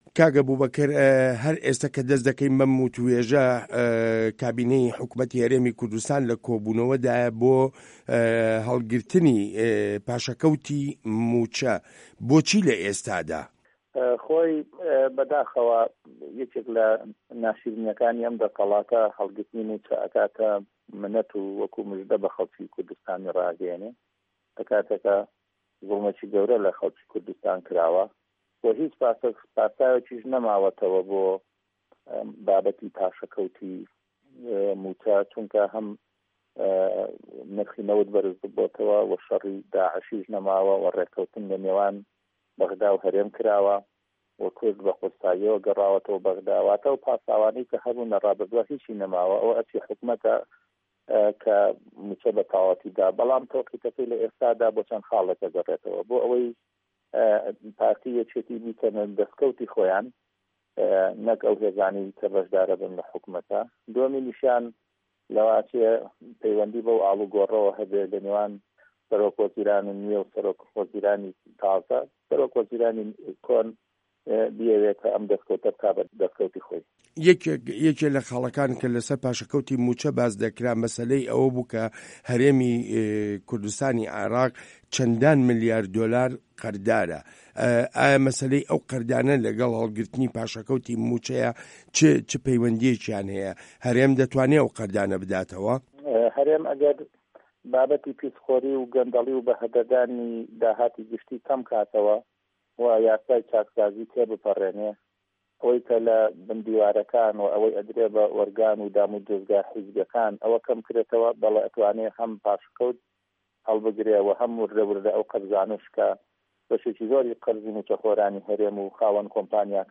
وتووێژ لەگەڵ ئەبوبەکر هەڵەدنی